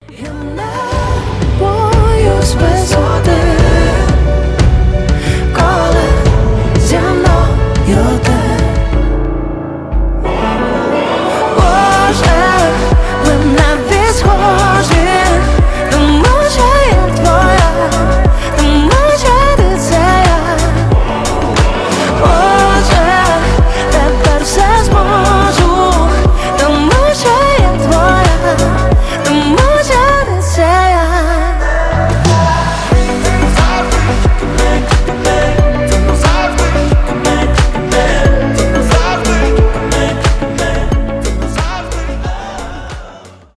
поп
красивый женский голос